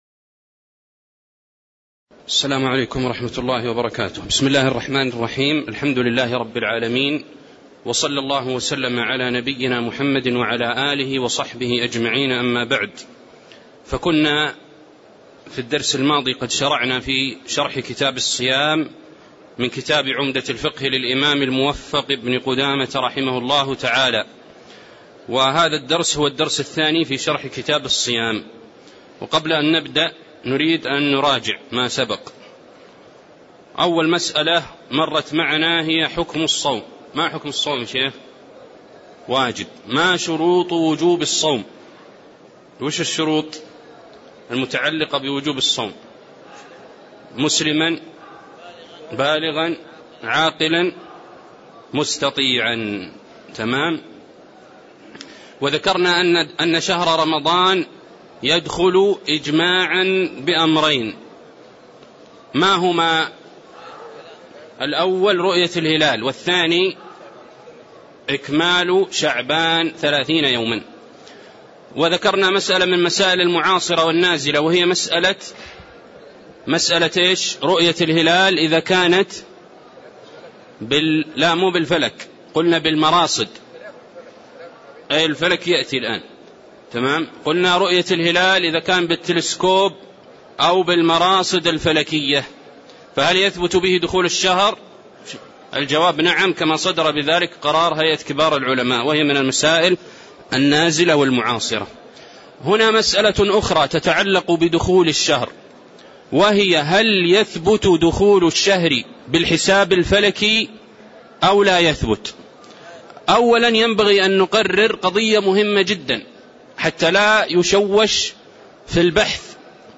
تاريخ النشر ٢٥ شعبان ١٤٣٧ هـ المكان: المسجد النبوي الشيخ